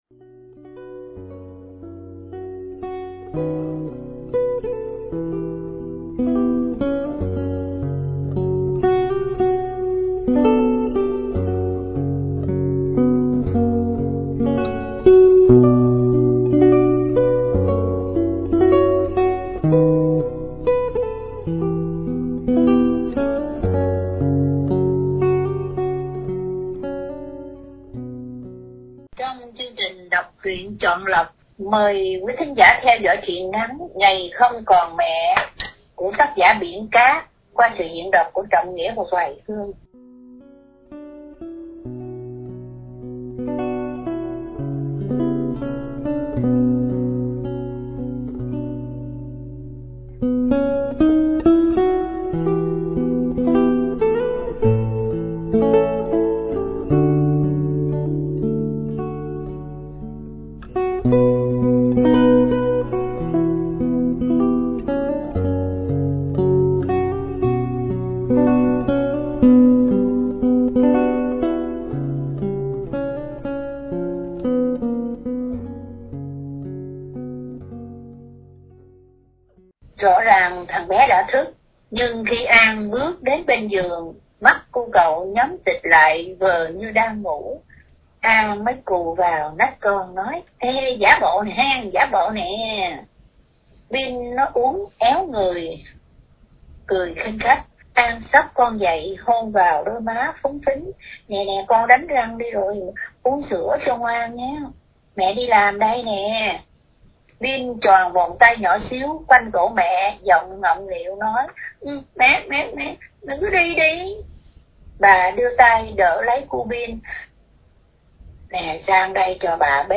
Đọc Truyện Chọn Lọc – Truyện Ngắn ” Ngày Không Còn Mẹ ” Biển Cát – Radio Tiếng Nước Tôi San Diego